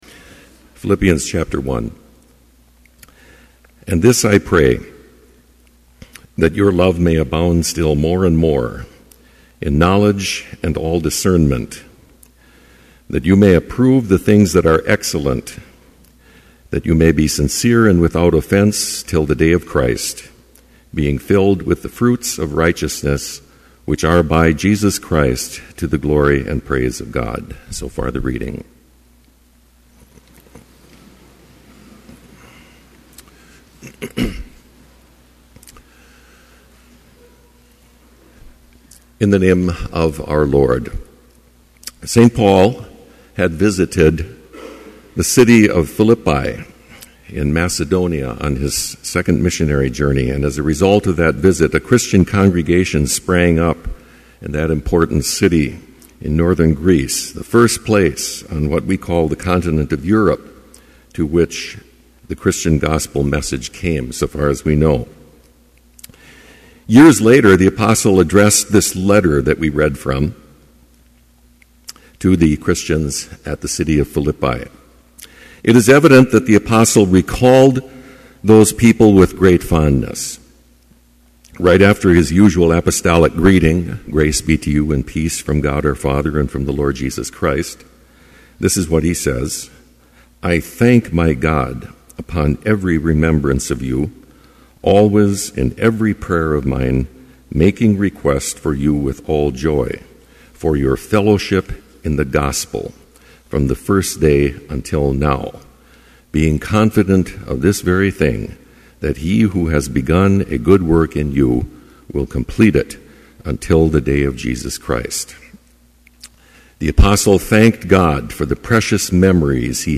Complete service audio for Chapel - August 31, 2011